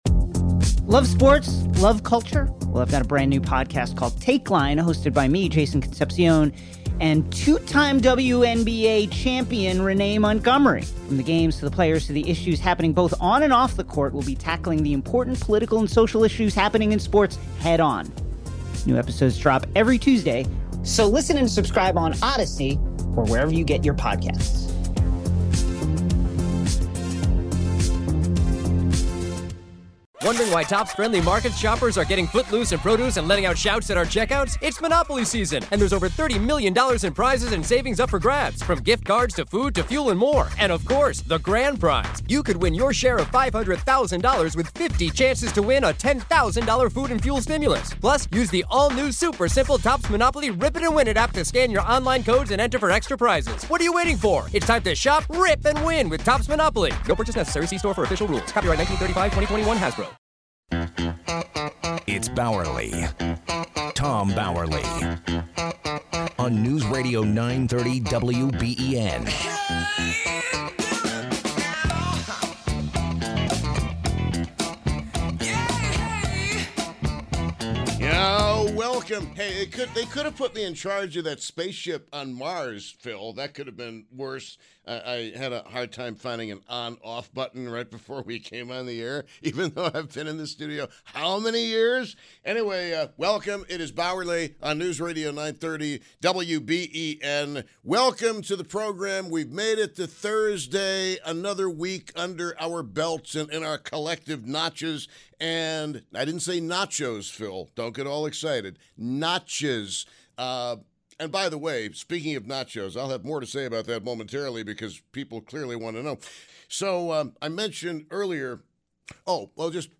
Board President Radio Interviews